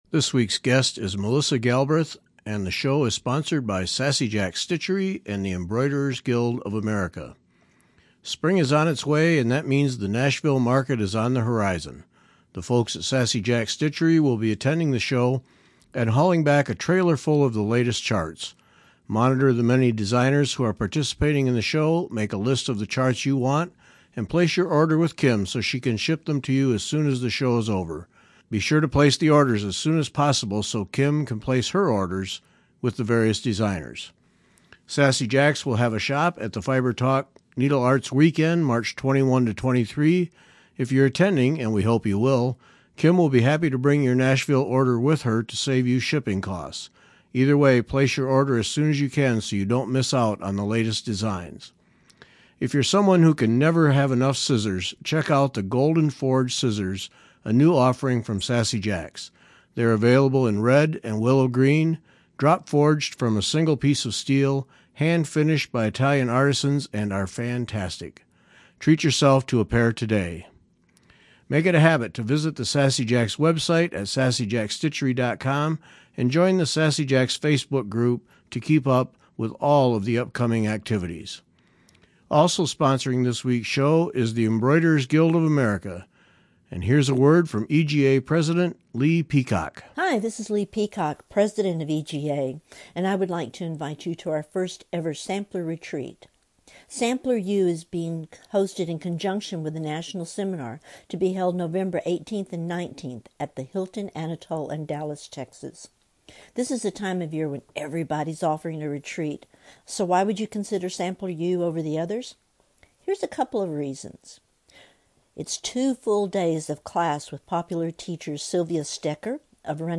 Enjoy the conversation, then go dig in your closet and find some old, boring shoes to embellish.